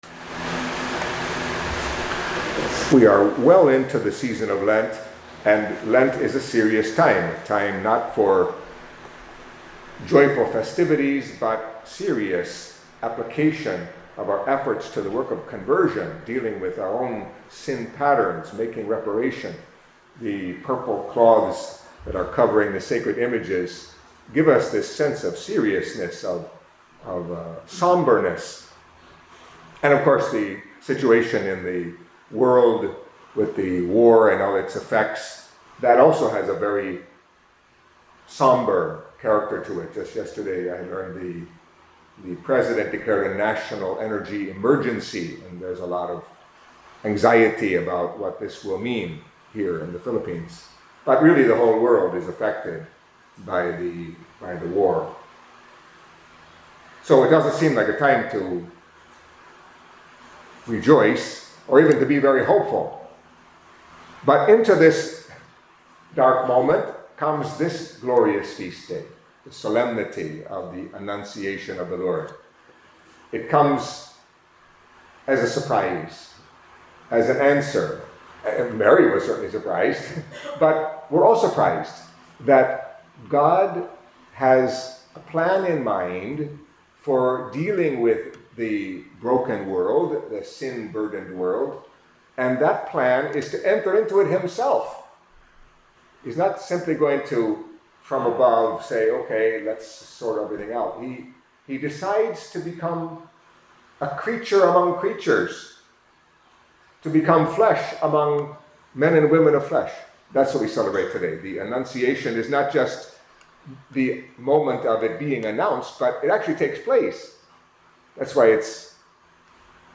Catholic Mass homily for Solemnity of the Annunciation of the Lord